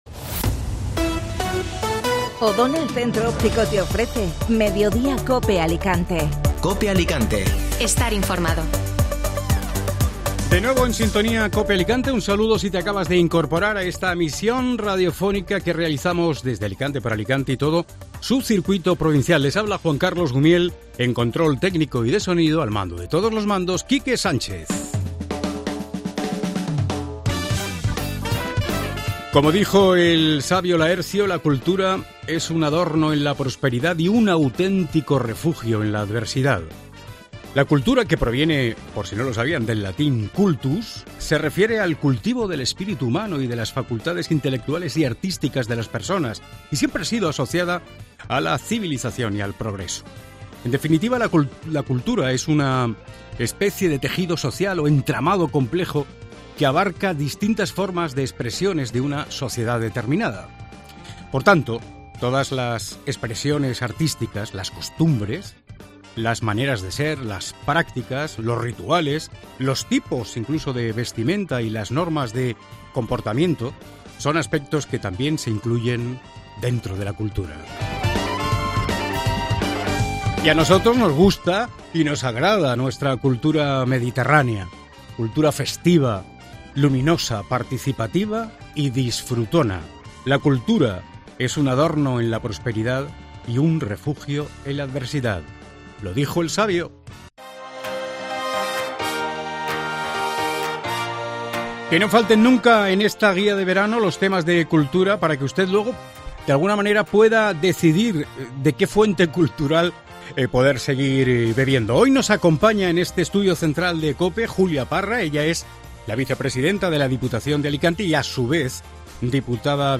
AUDIO: Escucha la entrevista a Julia Parra, vicepresidenta de la Diputación y diputada de Cultura